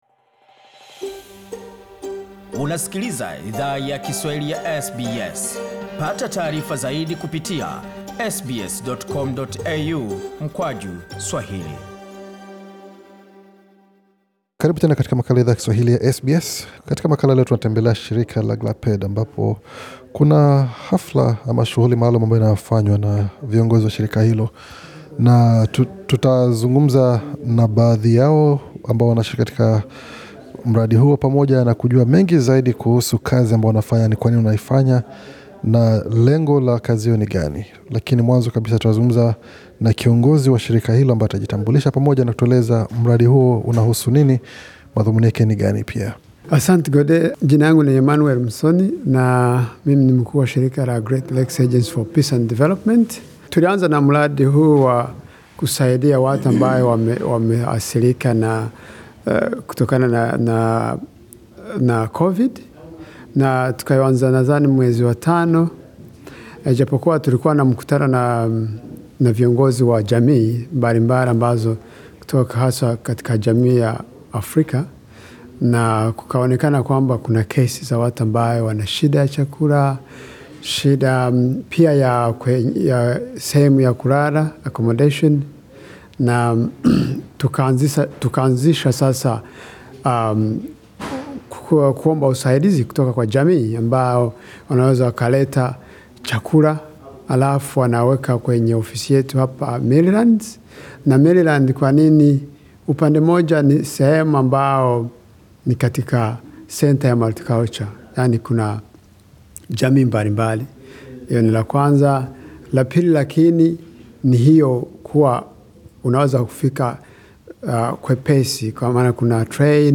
Idhaa ya Kiswahili ya SBS ilizungumza na baadhi ya viongozi wa shirika hilo pamoja nawatu walio pokea misaada kupitia mradi wakujaza mifuko ukarimu.